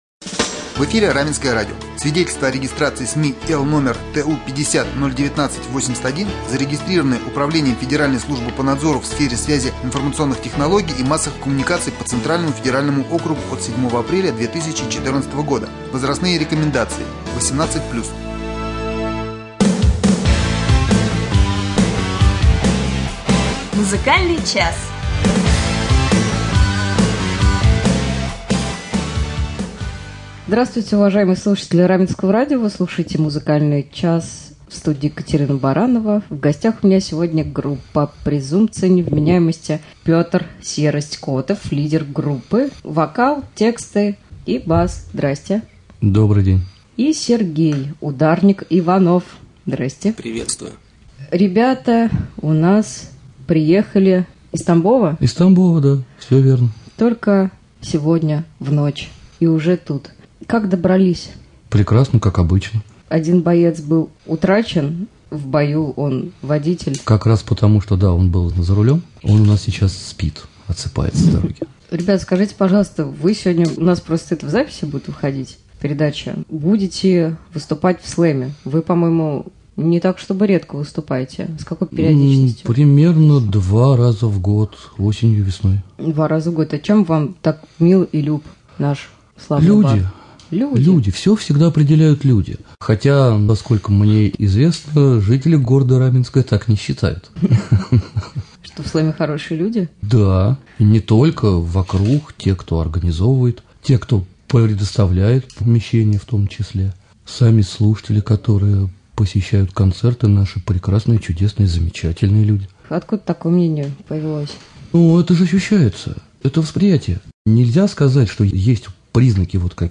Гости студии — участники группы «Презумпция Невменяемости»